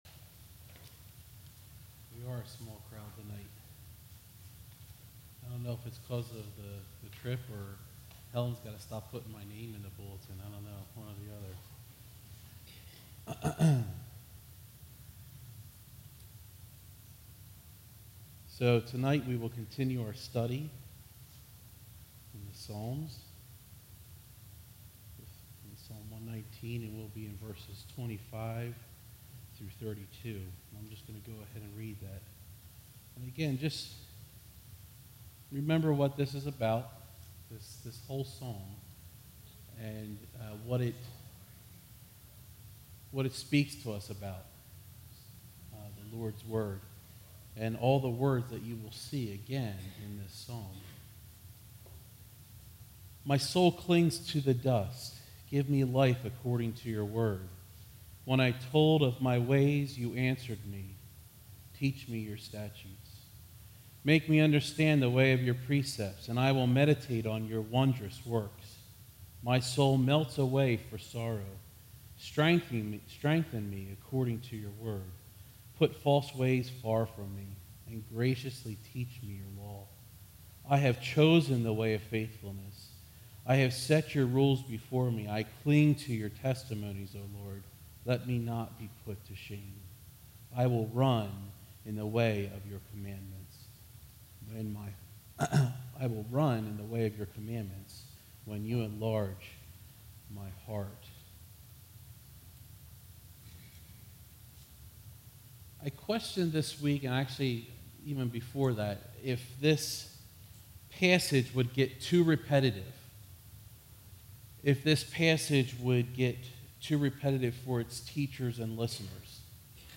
All Sermons Psalm 119:25-32